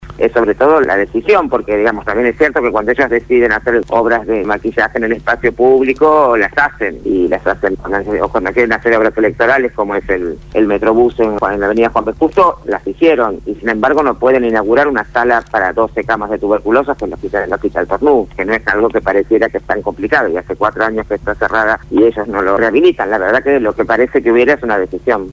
Gabriela Alegre, Legisladora de la Ciudad por el Encuentro Popular para la Victoria, habló con Radio Gráfica FM 89.3